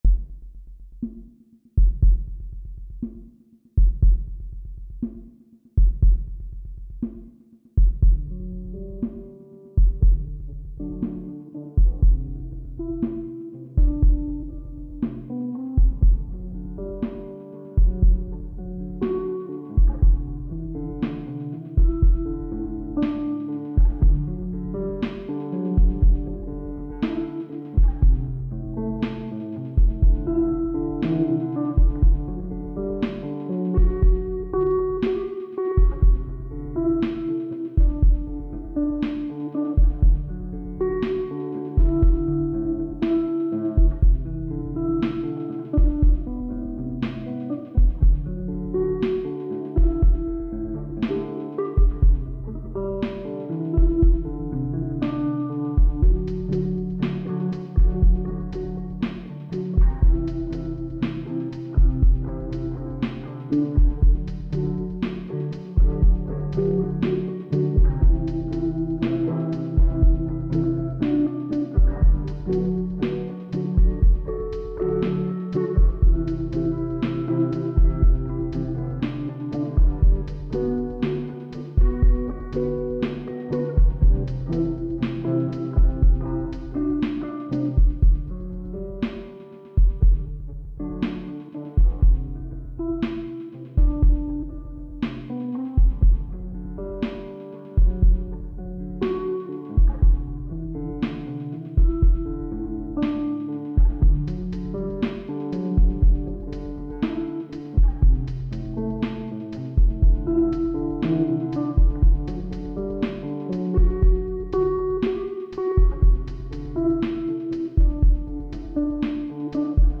Home > Music > Rnb > Smooth > Laid Back > Floating